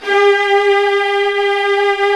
Index of /90_sSampleCDs/Roland LCDP13 String Sections/STR_Combos 1/CMB_Lrg Ensemble
STR SLOW V14.wav